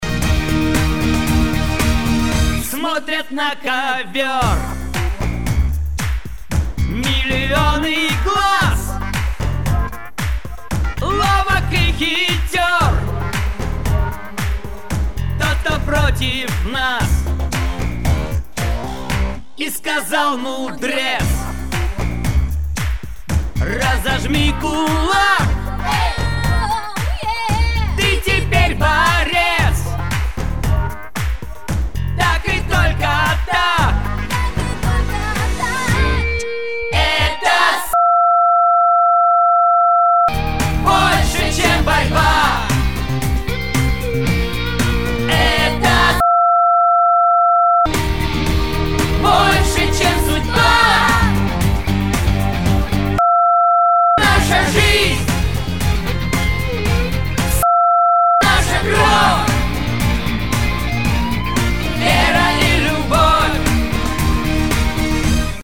Ключевые слова спрятаны под звуком "Пи-и-и-и".